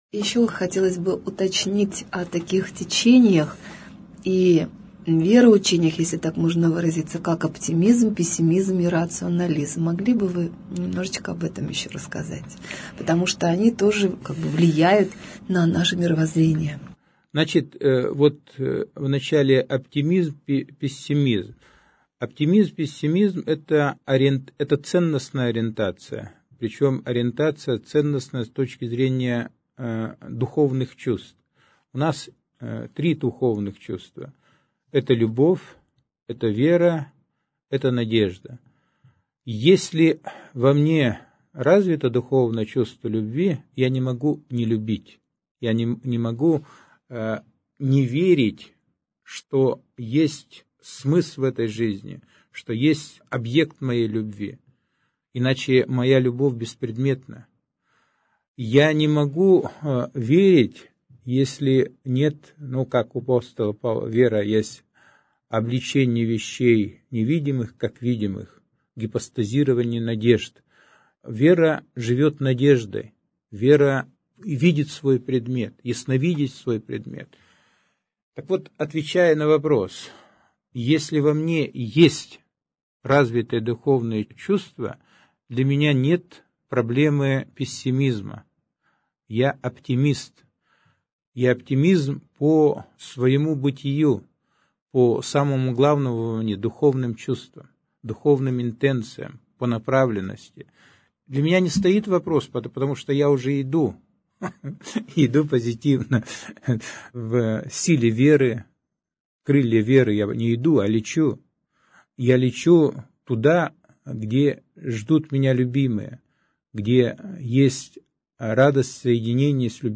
Гость в студии | Православное радио «Воскресение»